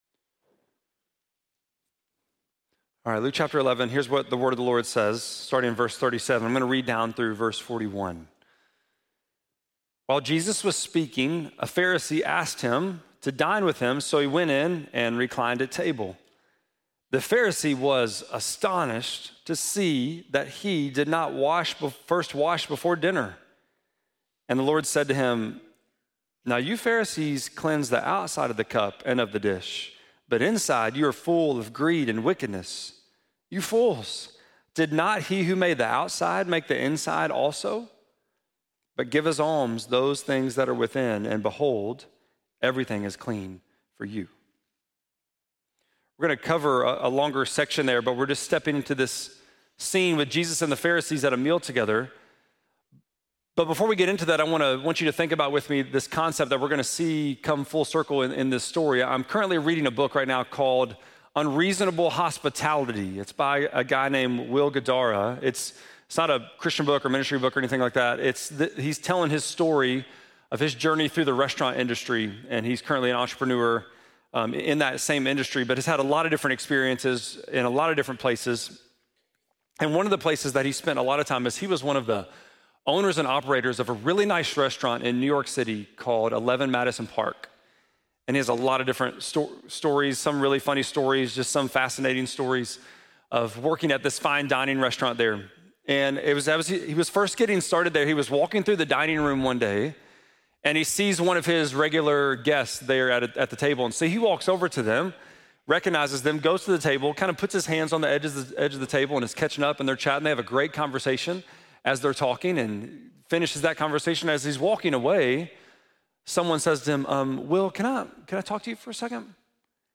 5.4-sermon.mp3